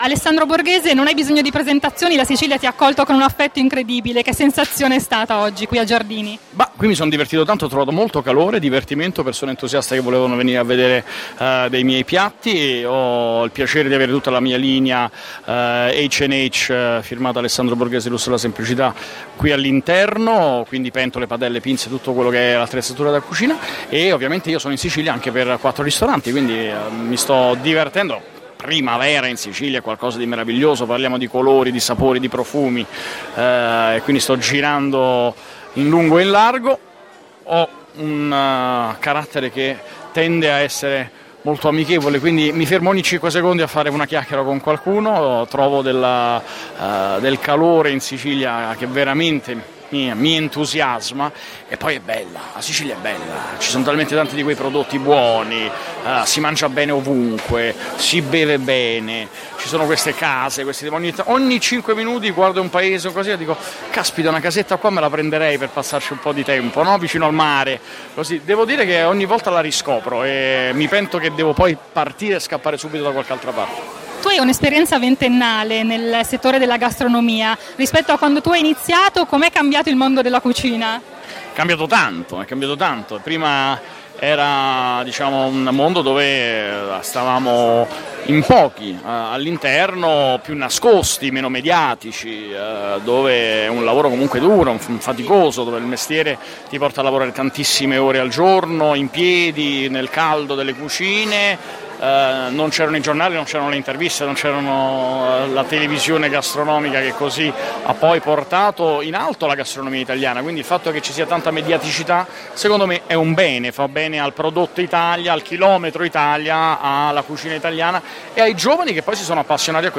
Le interviste di Taccuino Italiano